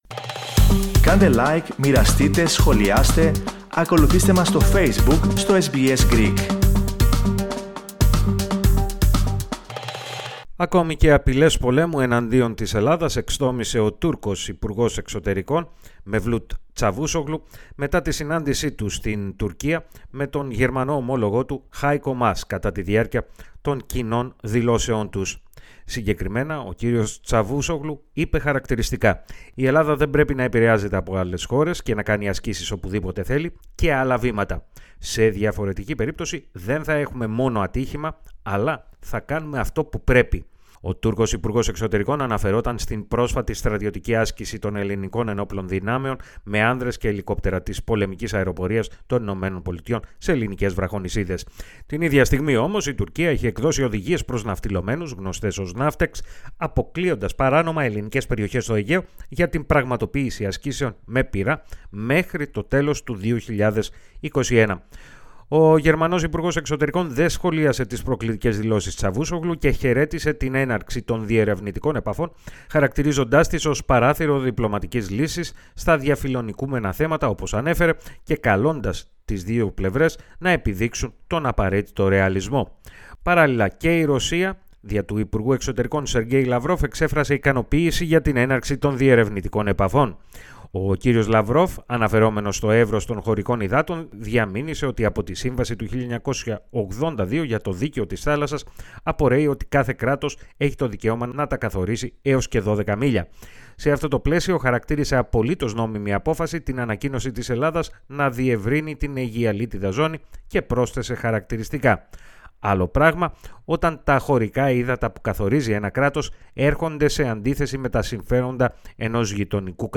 Συνεχίζονται οι προκλητικές δηλώσεις από μέρους της Τουρκίας, έξι μέρες πριν τη διεξαγωγή της πρώτης συνάντησης στην Κωνσταντινούπολη, στο πλαίσιο των διερευνητικών επαφών Ελλάδας – Τουρκίας. Περισσότερα, ακούστε στην αναφορά